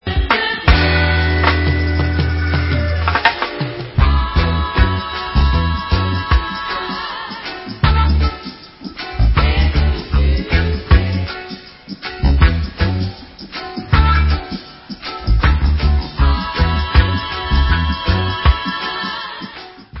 sledovat novinky v oddělení World/Reggae